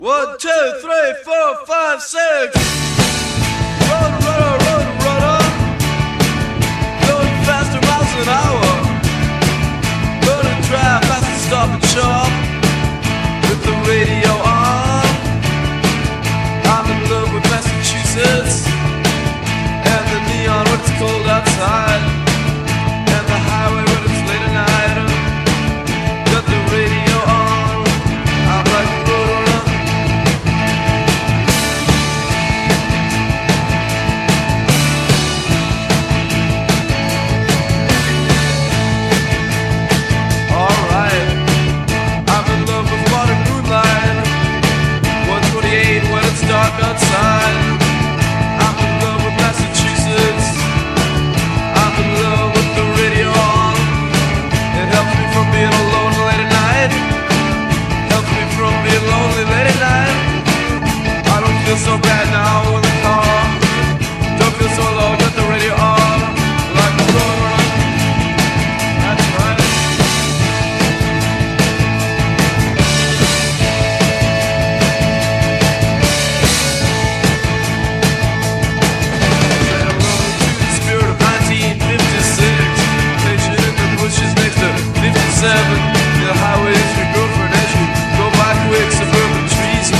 ROCK / PUNK / 80'S～ / ROCKABILLY / NEO ROCKABILLY
ボッピン・ネオ・ロカビリー！